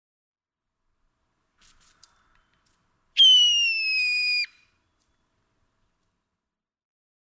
Blue jay
Imitation of a hawk, in apparent response to a nearby cat.
Amherst, Massachusetts.
357_Blue_Jay.mp3